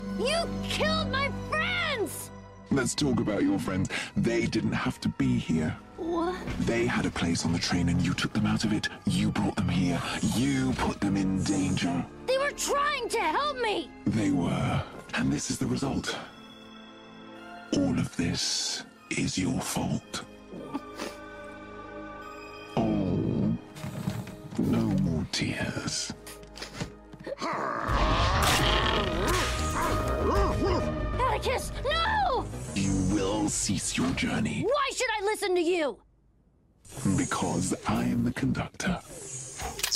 The double voice fits Nex so well!